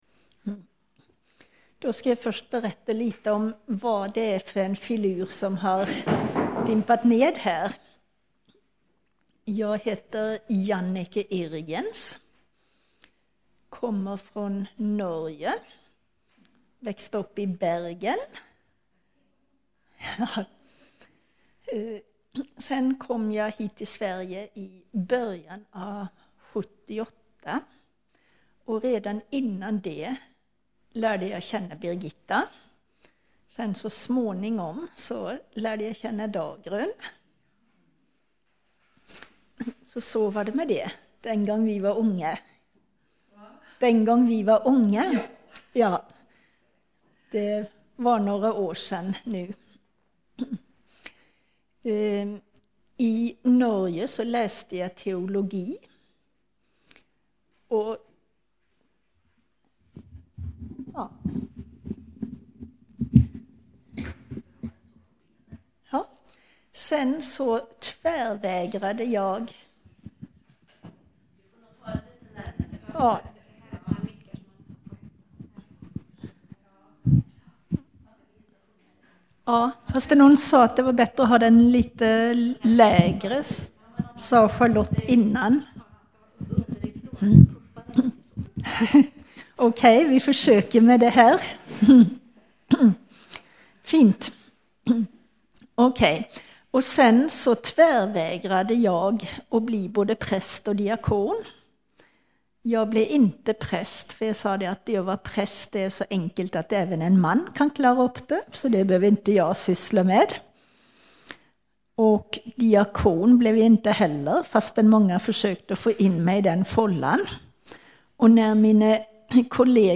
2013-04-14 Predikan av